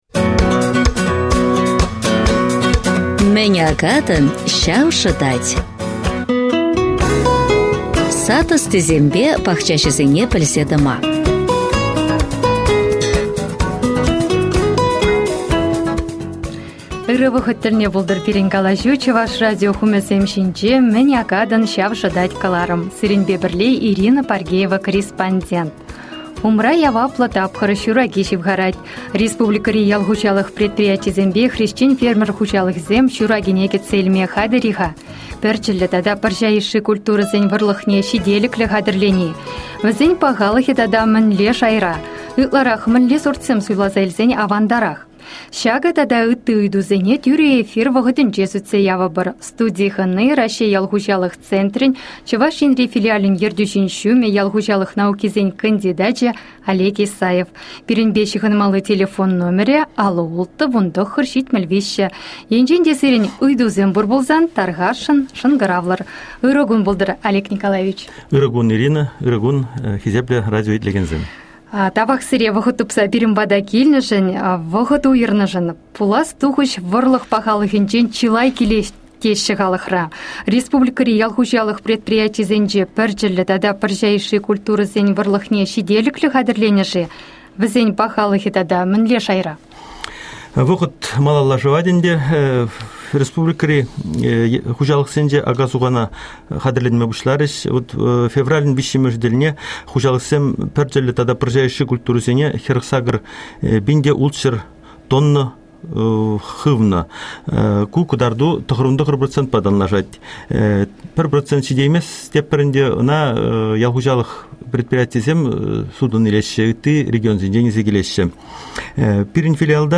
в прямом эфире «Радио Чувашии» (ГТРК «Чувашия») ответил на вопросы радиослушателей республики.